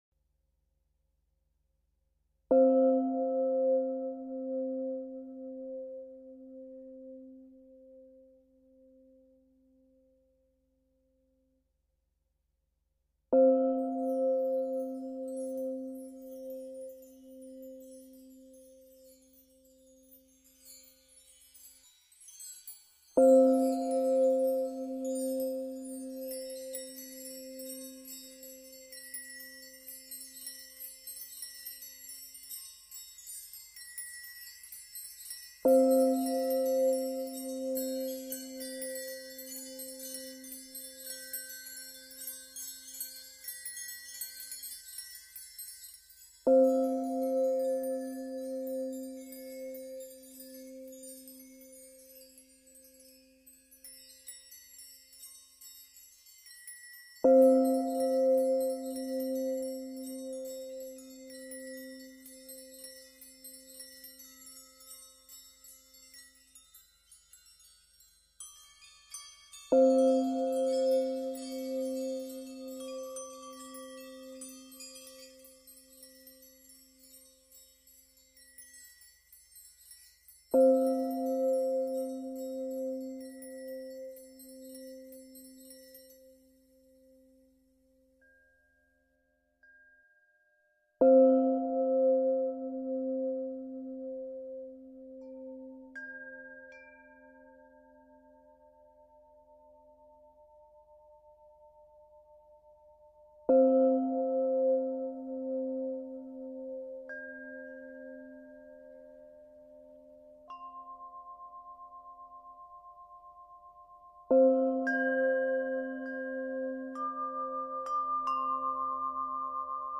Ce chant tibétain facilite la guérison de l’âme
CHANTS TIBÉTAINS
Chant-tibetain-pour-la-guerison-1.mp3